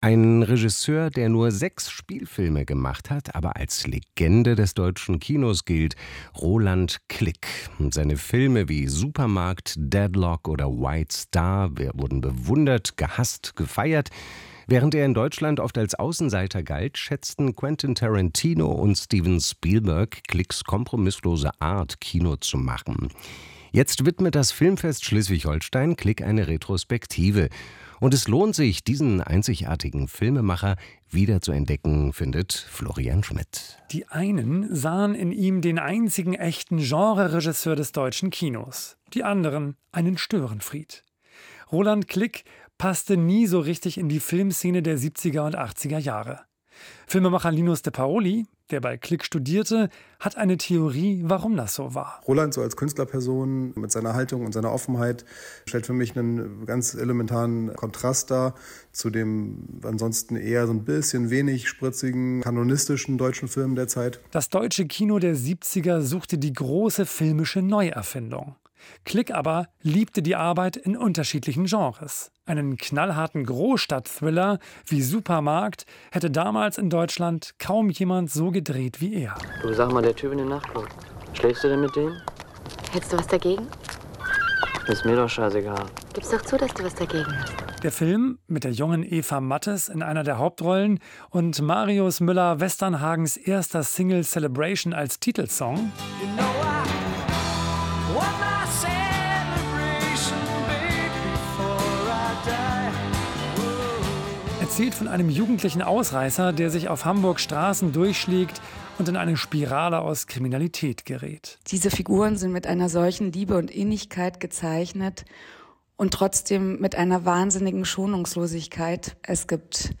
NDR Kultur Radio-Beitrag zur Retrospektive Roland Klick / Filmfest SH